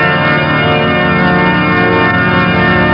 Big Organ Sound Effect
Download a high-quality big organ sound effect.
big-organ.mp3